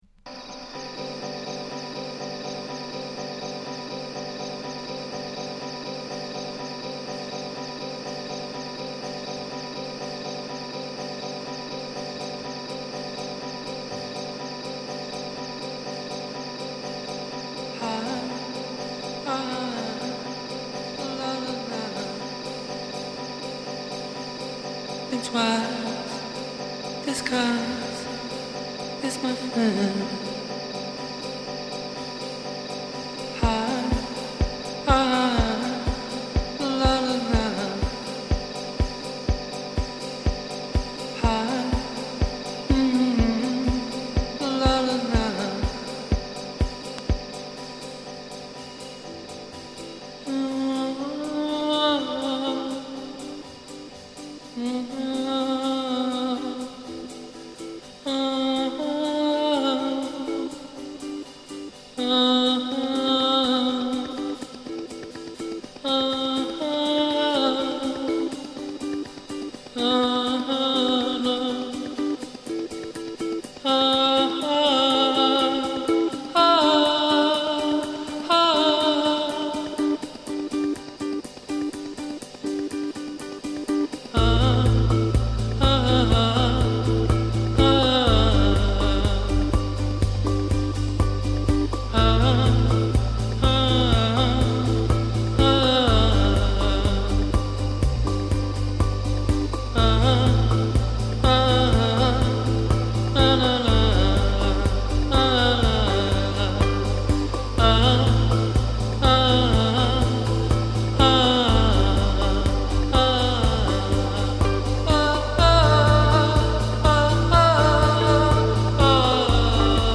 NO WAVE、ジャンク、アヴァンギャルドを軸に広義におけるダンスミュージックを集約したかのような意欲作。